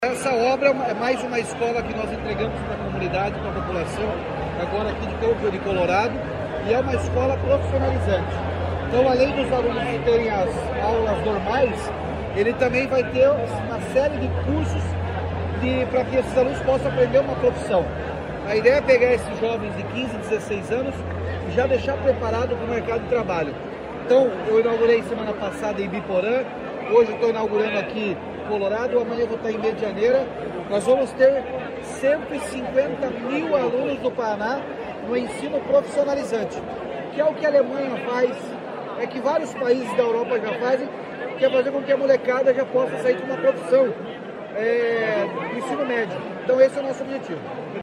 Sonora do governador Ratinho Junior sobre a inauguração do CEEPA de Colorado